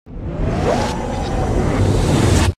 doorclose.ogg